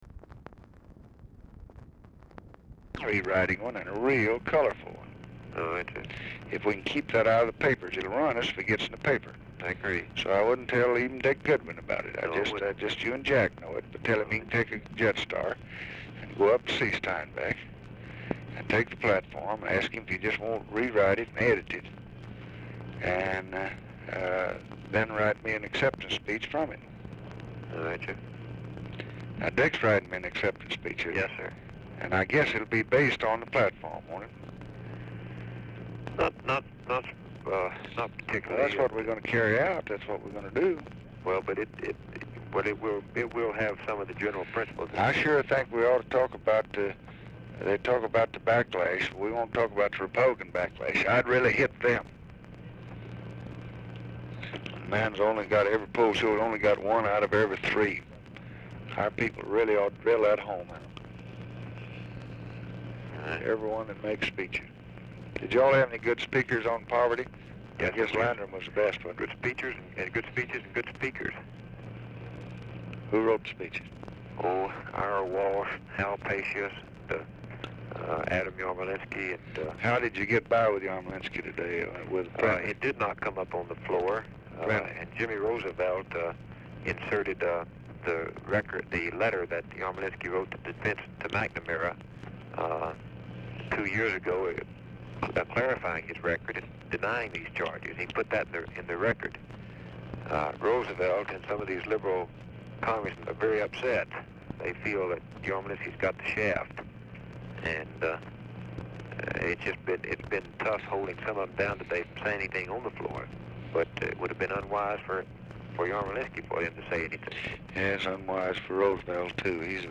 Telephone conversation
Format Dictation belt
Location Of Speaker 1 LBJ Ranch, near Stonewall, Texas